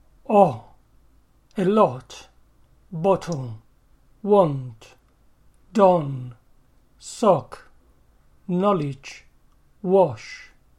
ɒ
a lot, bottle, want, don, sock, knowledge, wash
ɒ.mp3